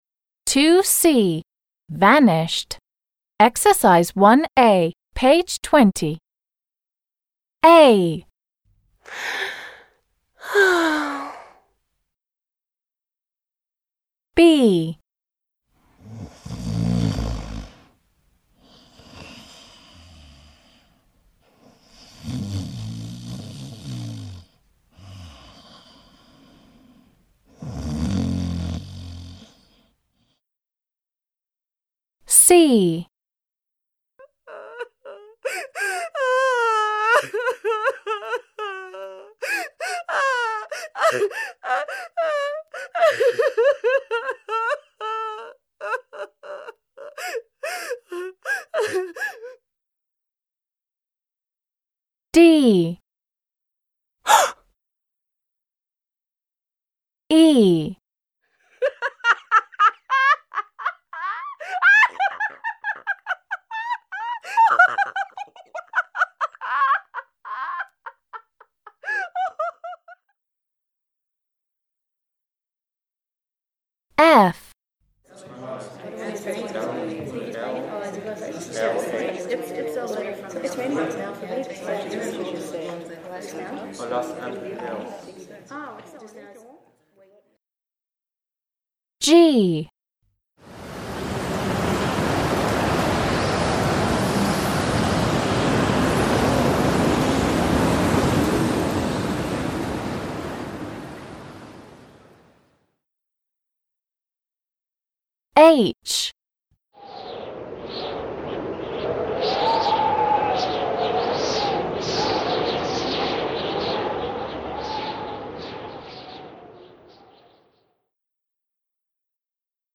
1. a) Listen and match the sounds 1-10 to the words a-j. Which of these sounds are made by people? − Послушай и сопоставь звуки 1-10 со словами a-j. Какие из этих звуков издаются людьми?
1. a) sigh − вздох
2. b) snore − храп
3. с) cry − плач
4. d) gasp − ох!
5. e) laugh − смех
6. f) chat − болтовня
7. g) storm − шторм
8. h) gust of wind − порыв ветра
9. i) whisper − шепот
10. j) yawn − зевок
All of these sounds can be made by people except storm and gust of wind − Все эти звуки могут издаваться людьми, кроме звуков шторма и порывов ветра.